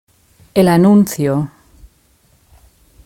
Pronunciation Es El Anuncio (audio/mpeg)